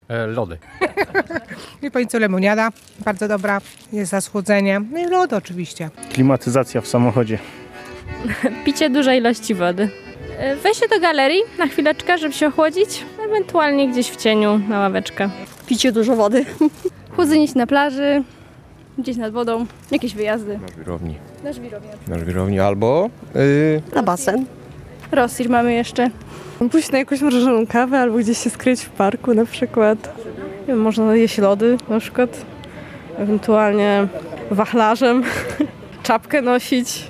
Wrócił upał. Jak sobie radzą z nim mieszkańcy Rzeszowa? [SONDA]